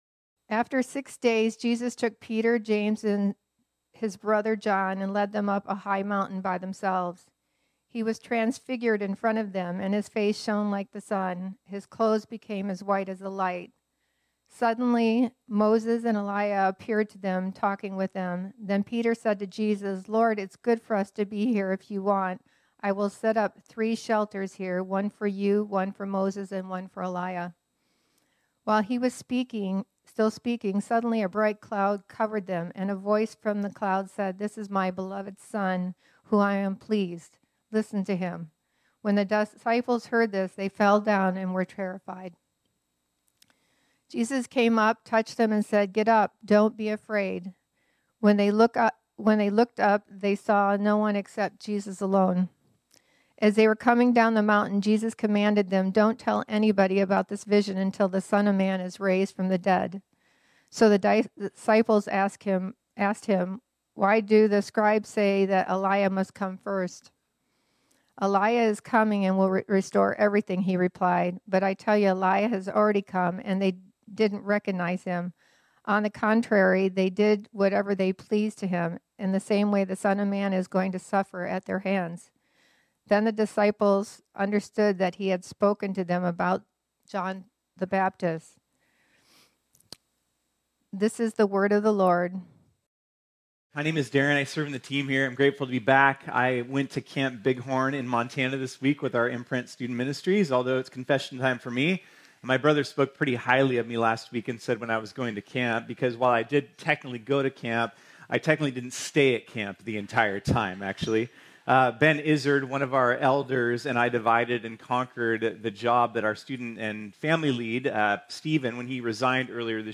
This sermon was originally preached on Sunday, August 4, 2024.